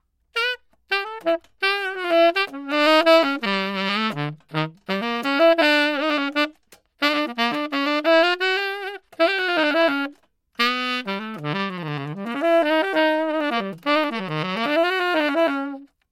快速吹一口气
描述：快速吹一口气的声音。
声道单声道